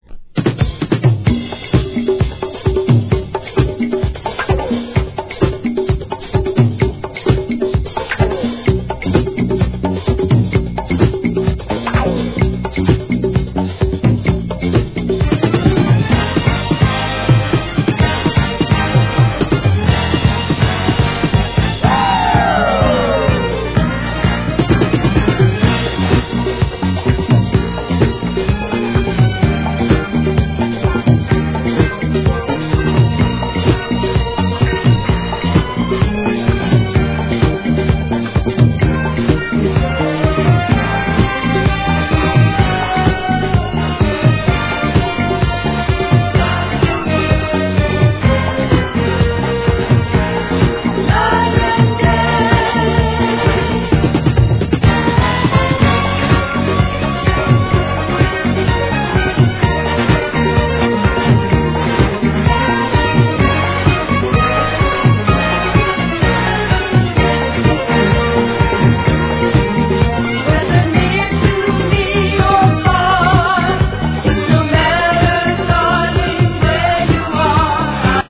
1. SOUL/FUNK/etc...
ゴージャスなDANCE CLASSIC!!